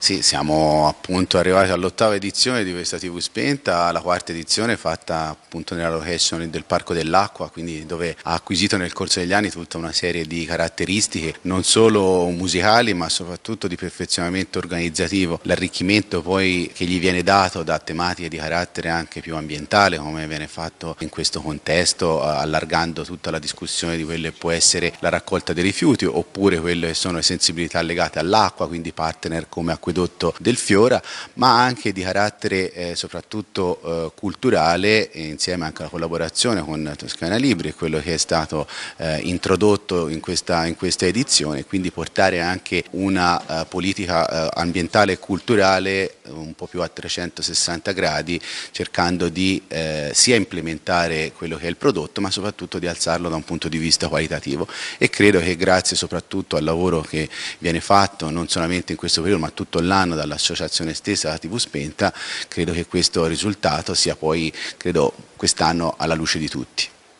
Emiliano Spanu (sindaco Rapolano)